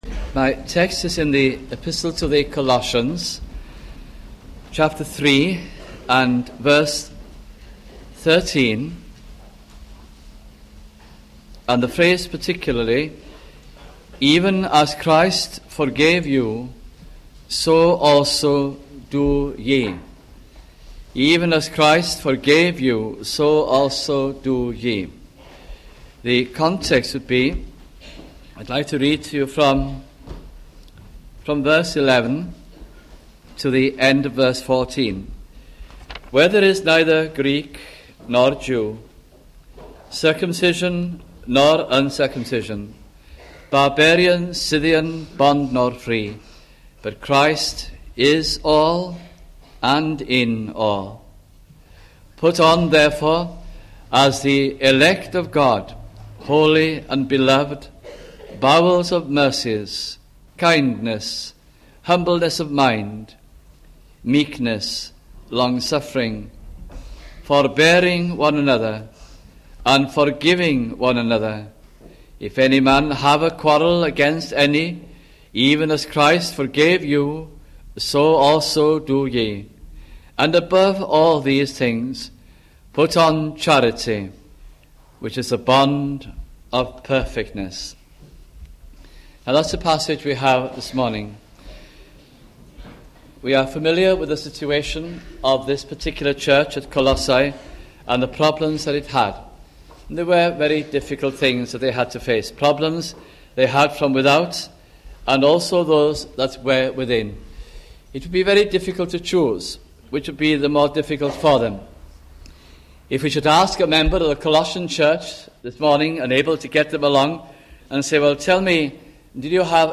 » Colossians Series 1988 - 1989 » sunday morning messages